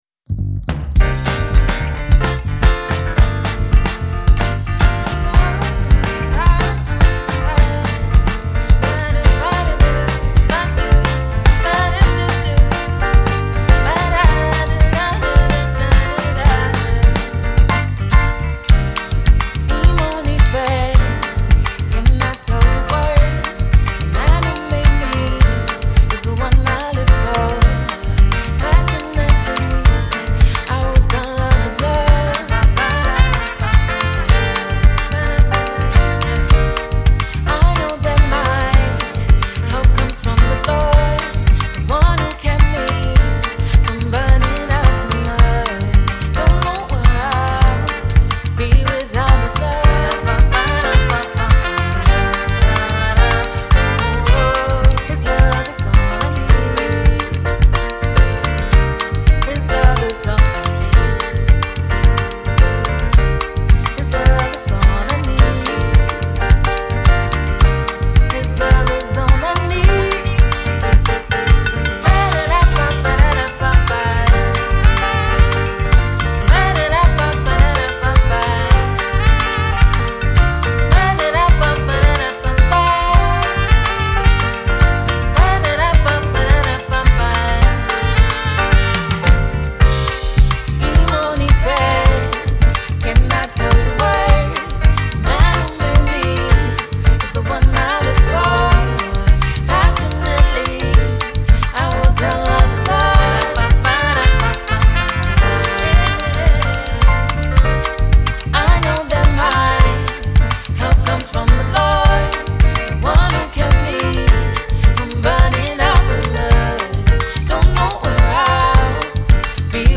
Canadian Based band